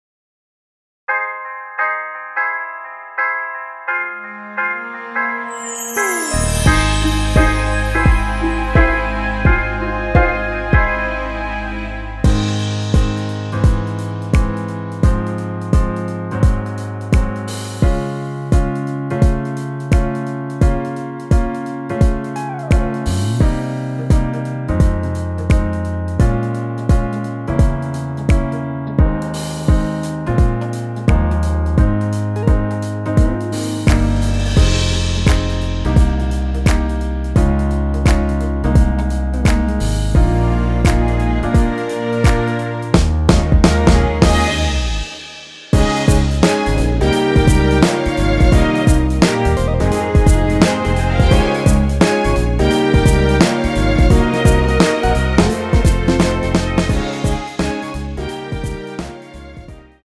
MR입니다.
엔딩이 페이드 아웃이라서 노래하기 편하게 엔딩을 만들어 놓았으니 코러스 MR 미리듣기 확인하여주세요!
앞부분30초, 뒷부분30초씩 편집해서 올려 드리고 있습니다.
중간에 음이 끈어지고 다시 나오는 이유는